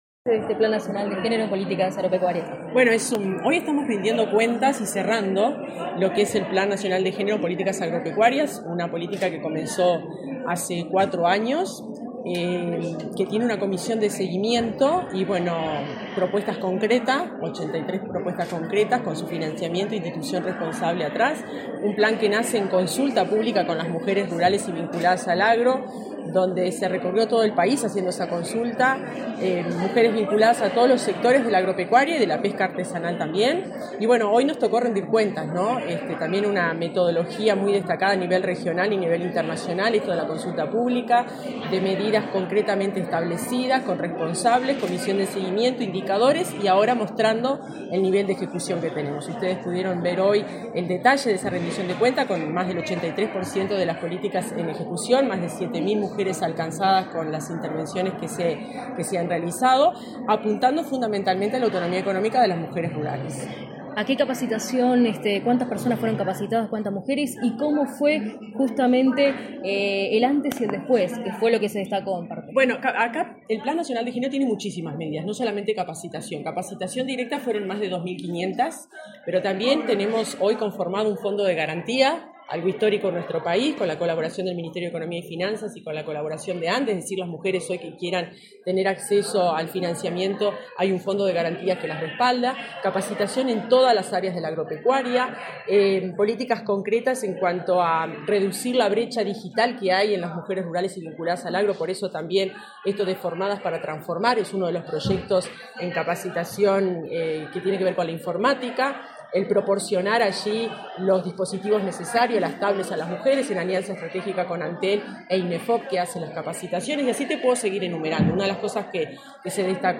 Entrevista a la directora general del MGAP, Fernanda Maldonado